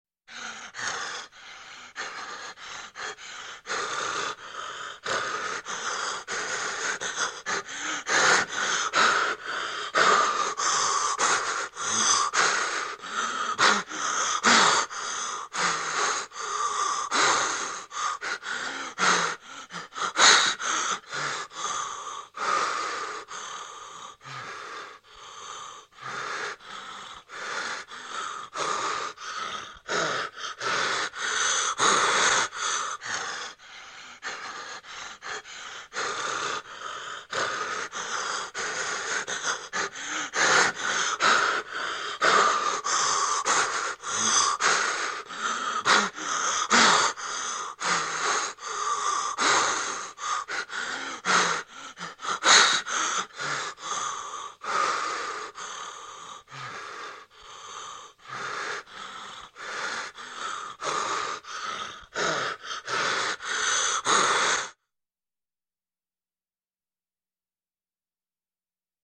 Испуганное дыхание
ispugannoe_dihanie_9ic.mp3